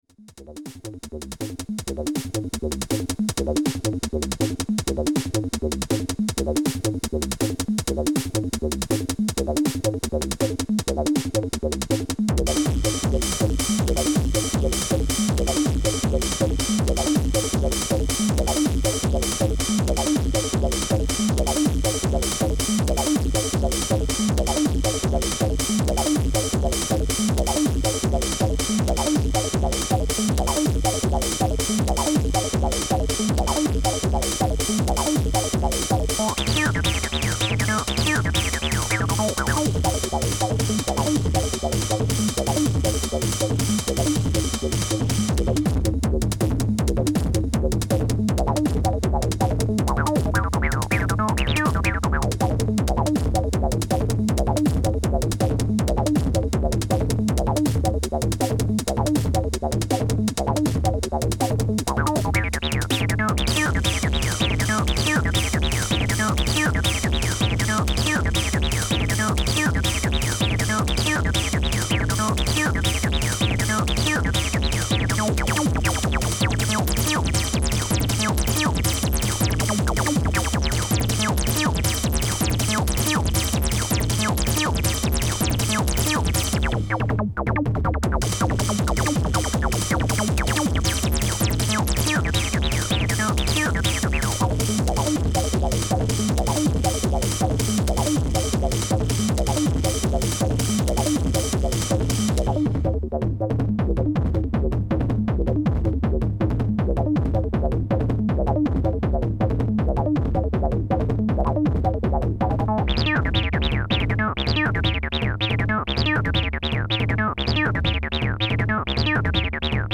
acid basslines, oldskool wave